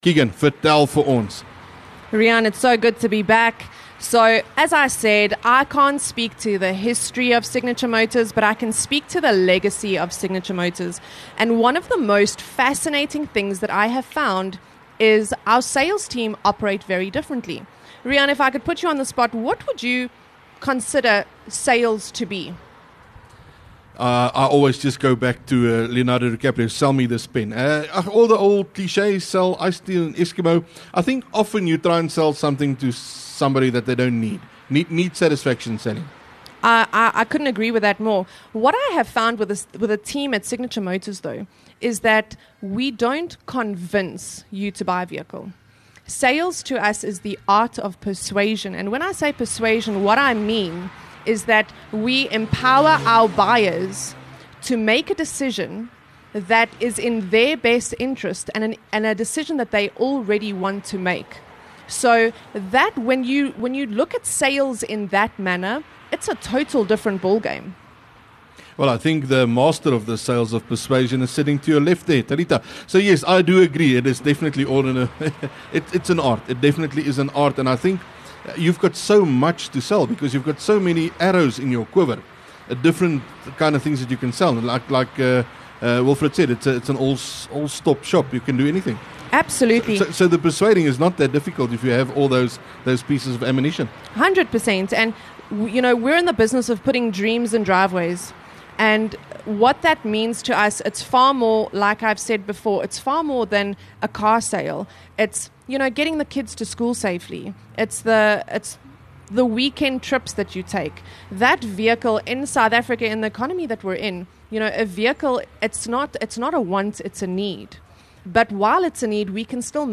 LEKKER FM | Onderhoude 7 Mar Signature Motors